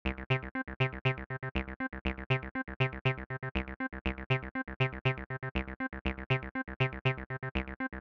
Get More Groove with Accents
2-2_Accents_TB-303_Example.mp3